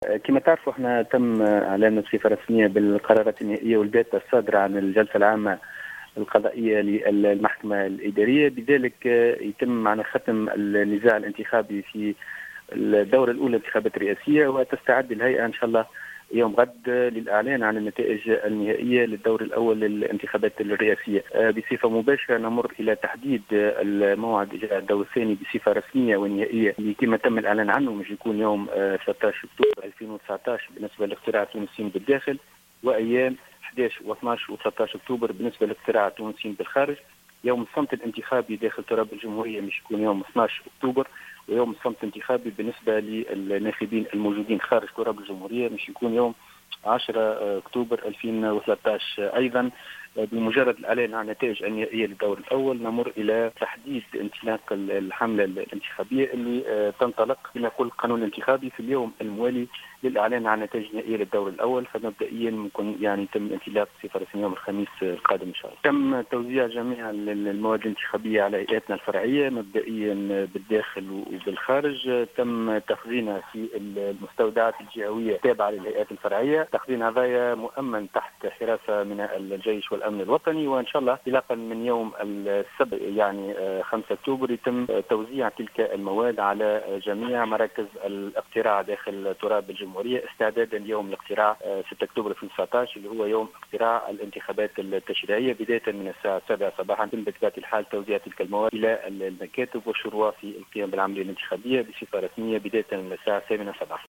أفاد عضو مجلس الهيئة العليا المستقلة للانتخابات، "فاروق بوعسكر"، في تصريح لـ "الجوهرة اف أم" اليوم بأنه سيتم غدا الأربعاء تنظيم ندوة لإعلان كل التفاصيل الخاصة بالانتخابات الرئاسيّة في دورتها الثانية. وأضاف أنّ الهيئة تستعد للإعلان خلال هذه الندوة الصحفية عن النتائج النهائية للدوْر الأول للانتخابات الرئاسية بالإضافة إلى الإعلان الرسمي عن موعد الدور الثاني والذي سيكون يوم 13 أكتوبر بالداخل وأيام 11 و12 و13 اكتوبر بالنسبة للخارج. وتابع أنّ يوم الصمت الانتخابي سيكون يوم 12 من الشهر الحالي في تونس ويوم 10 من نفس الشهر بالخارج.